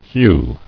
[hew]